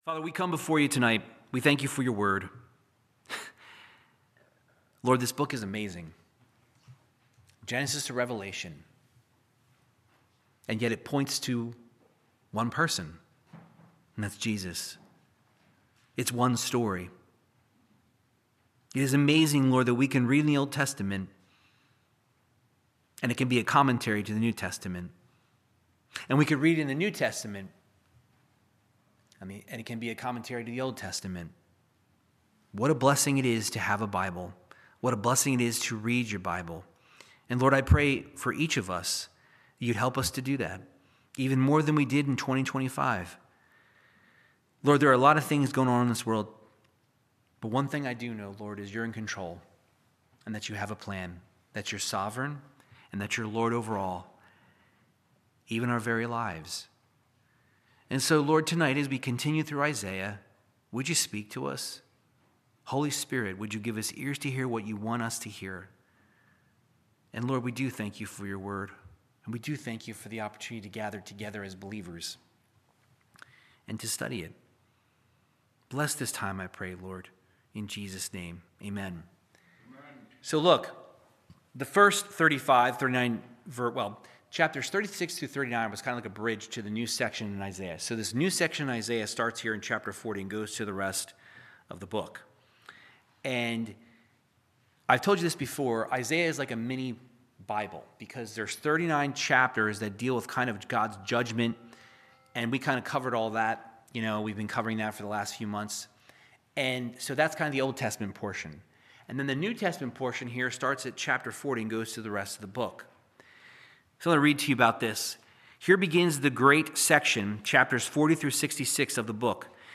Verse by verse Bible teaching through the book of Isaiah chapter 40